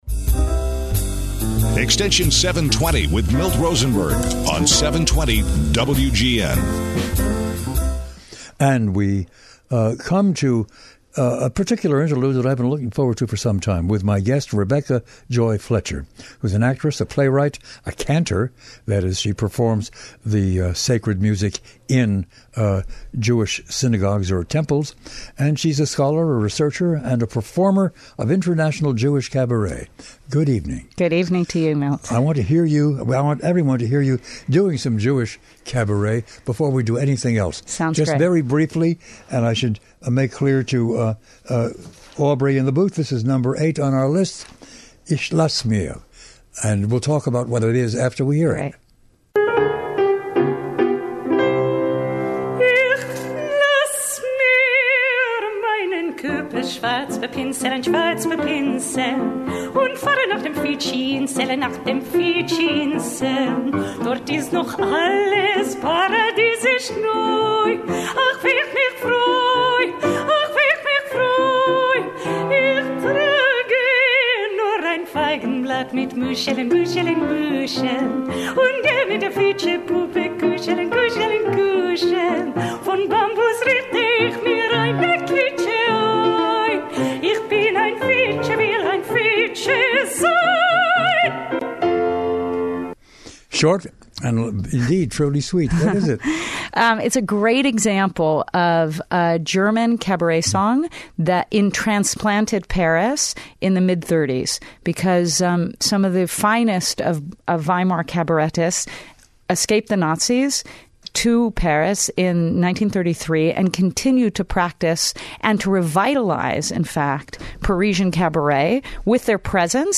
Milt Rosenberg is a daily podcast that features provocative and thought provoking discussion centered on the world of ideas.